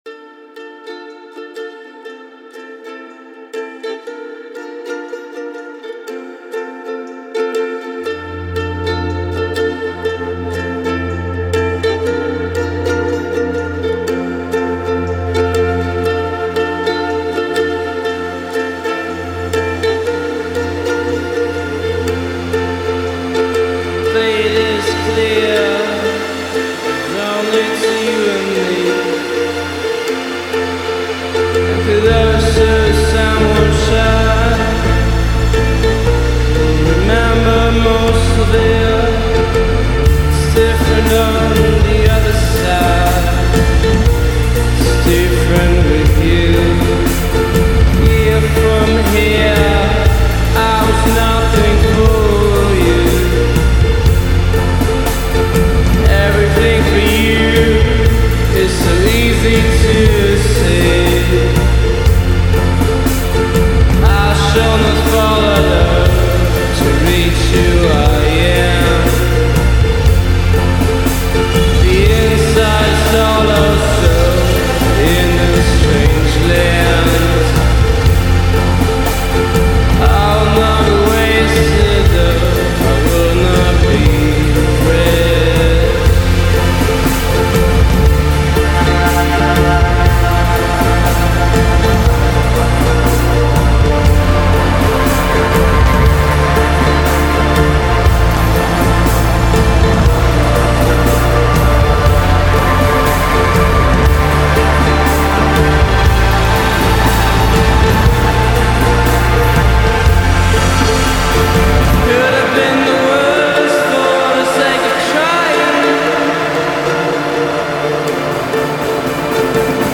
post-punk-leaning sound